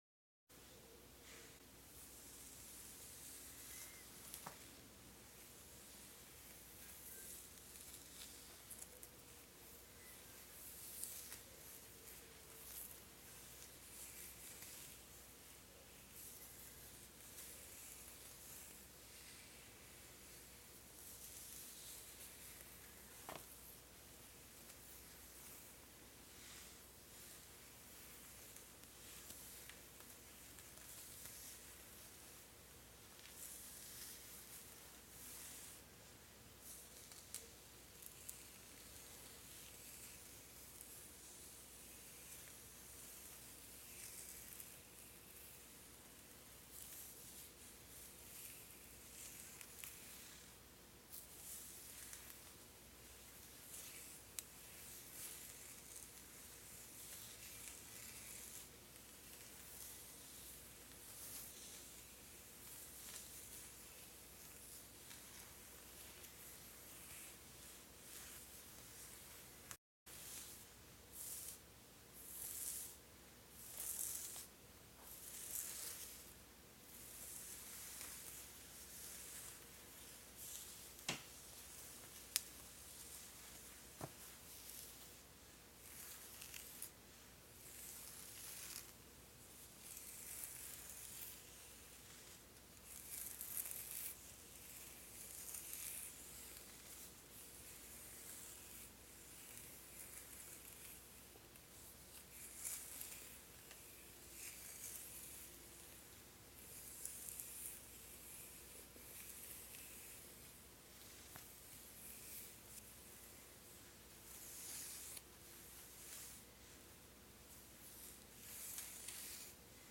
Hair play ASMR with some relaxing scratches